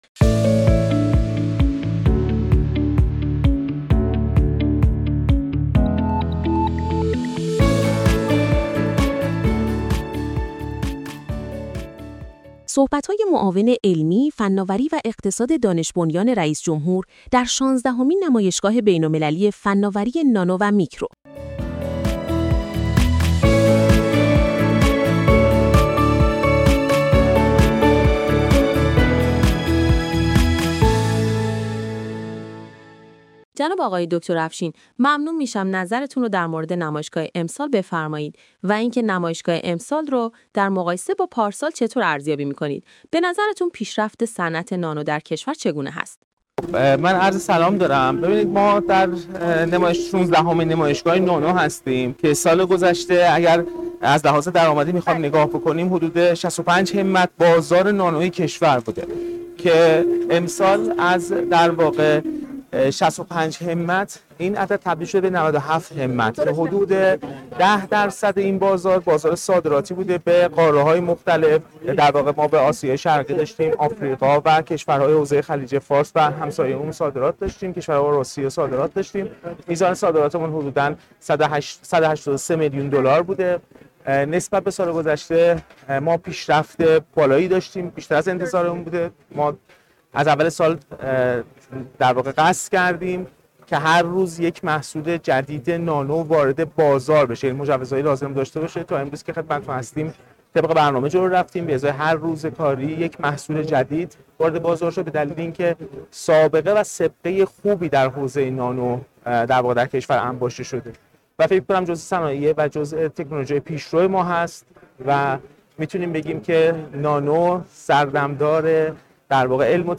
حسین افشین معاون علمی، فناوری و اقتصاد دانش‌بنیان رئیس‌جمهور، در شانزدهمین نمایشگاه فناوری نانو در گفت‌و‌گو با آناتک از رشد ۴۹ درصدی بازار فناوری نانو در کشور خبر داد و گفت: ارزش این بازار از ۶۵ همت سال گذشته به ۹۷ همت در سال ۱۴۰۳ رسیده و صادرات محصولات نانویی ایران نیز به ۱۸۳ میلیون دلار افزایش یافته است.